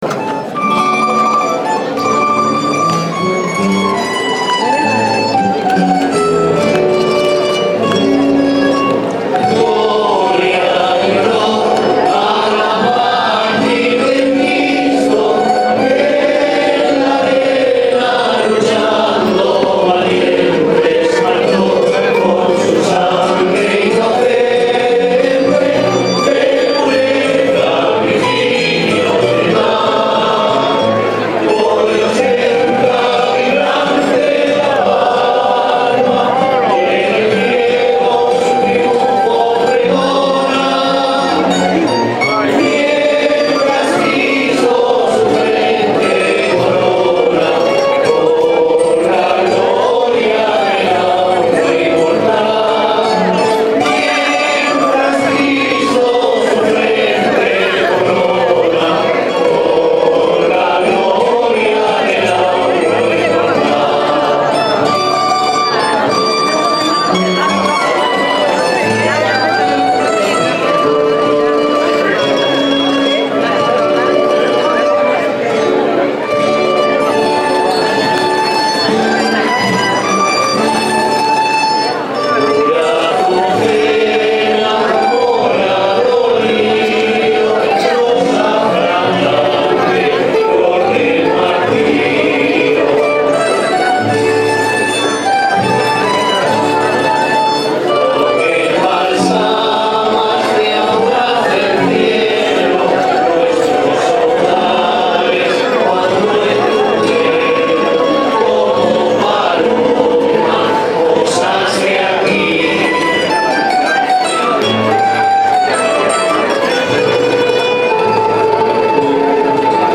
Romería Santa Eulalia. 7 enero 2014. Llegada a La Santa y Misa